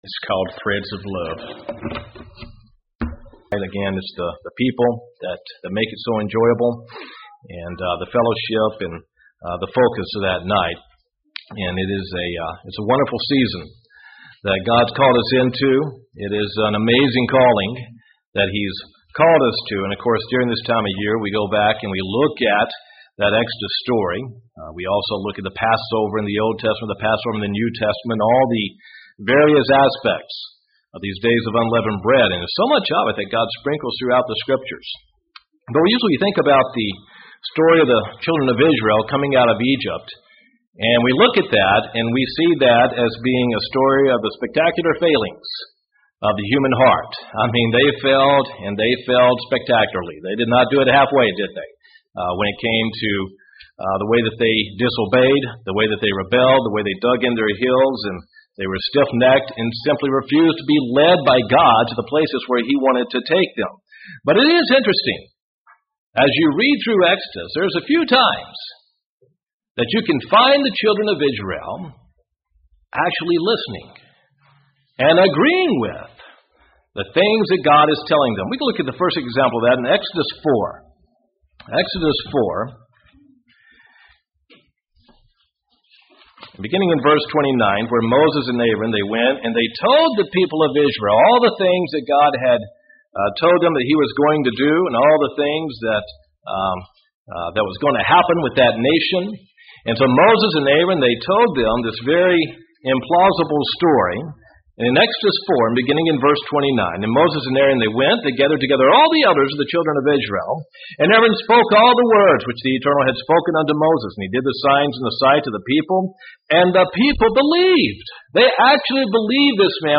Given in Gadsden, AL Huntsville, AL